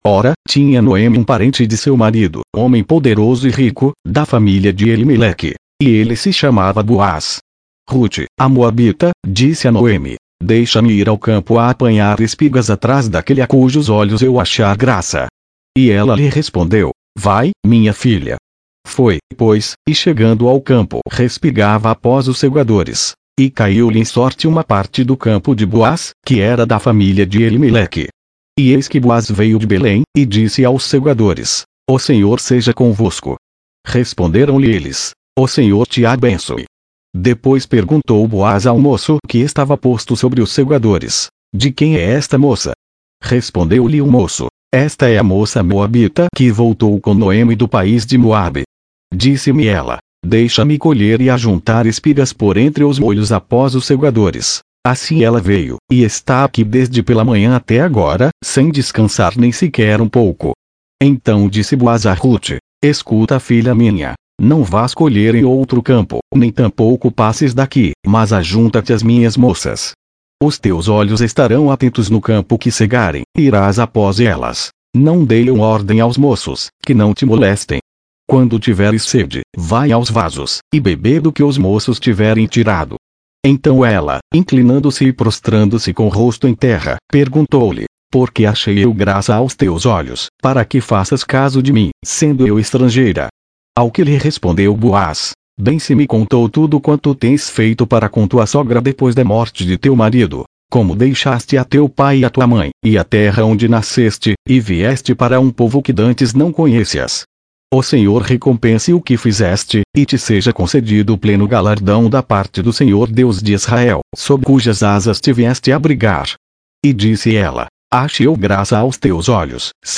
Reading in version Revisada - Portuguese